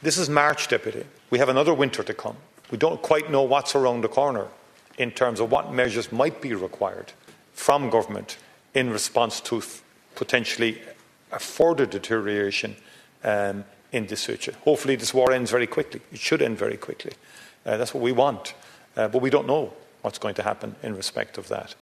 Taoiseach Michael Martin told the chamber, the coalition has to prepare for what’s still to come……………